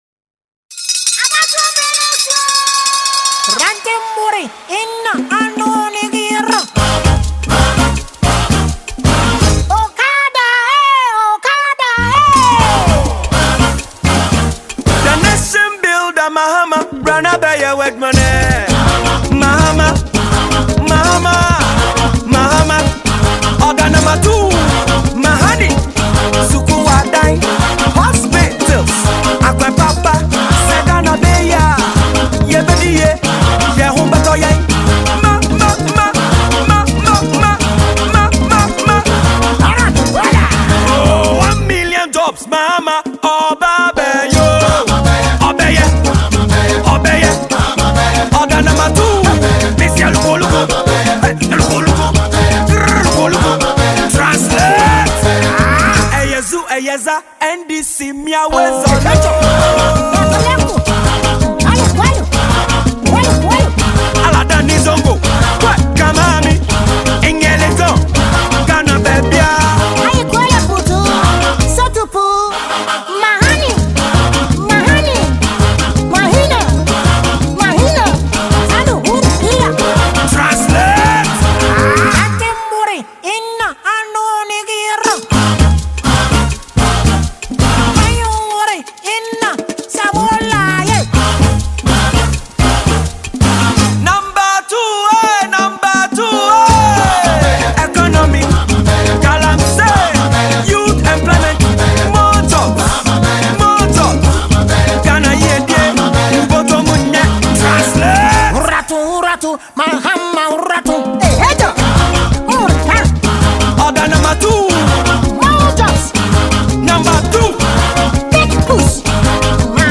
a Ghanaian songwriter and singer